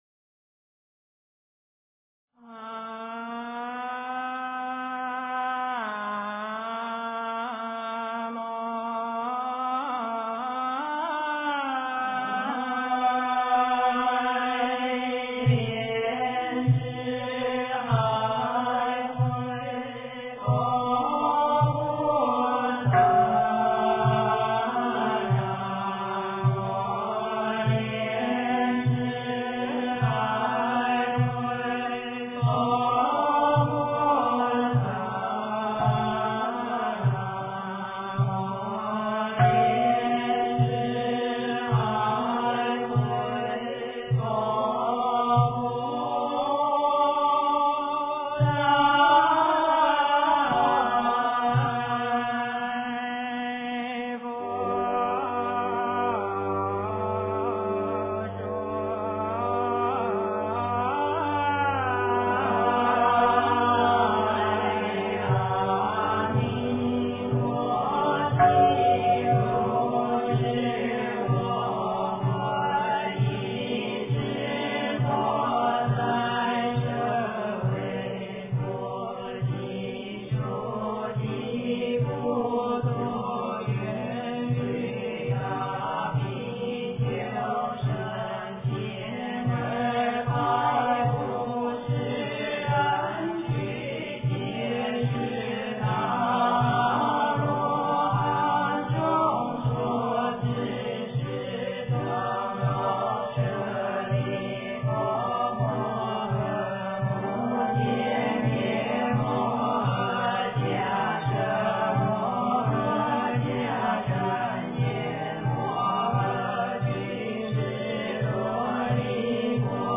经忏
佛音
佛教音乐